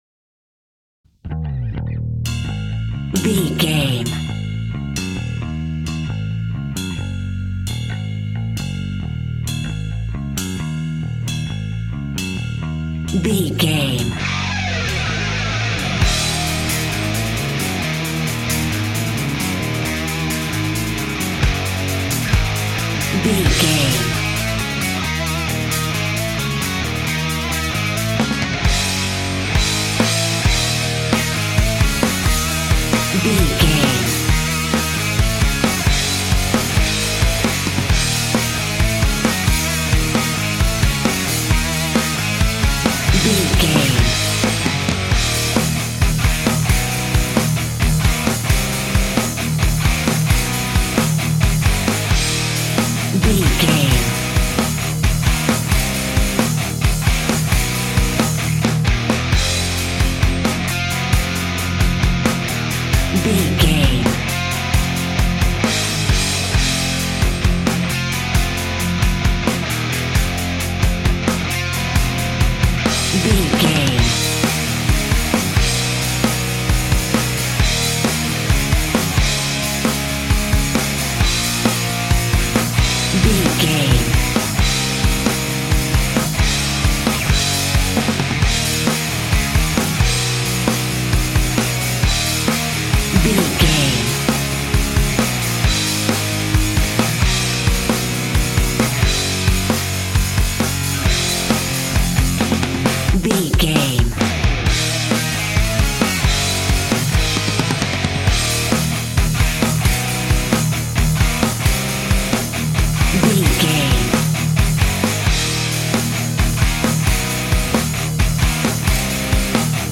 Ionian/Major
E♭
energetic
driving
heavy
aggressive
electric guitar
bass guitar
drums
hard rock
heavy metal
distortion
distorted guitars
hammond organ